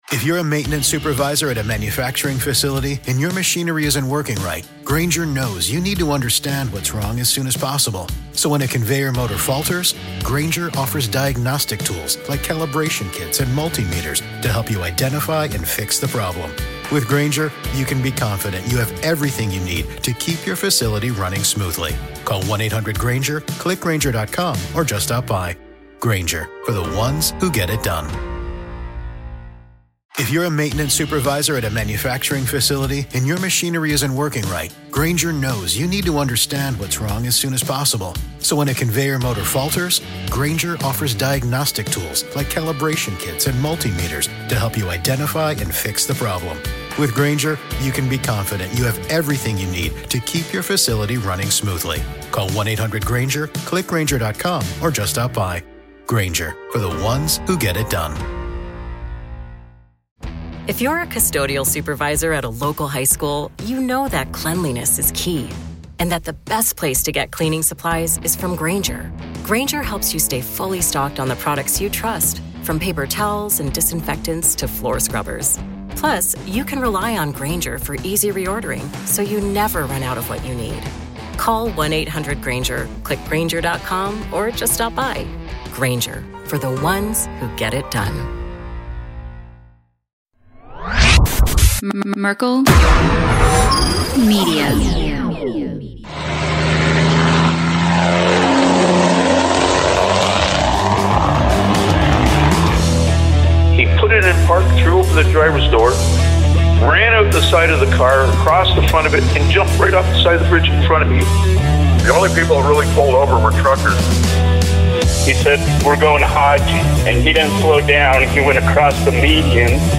This was a laugh-out-loud episode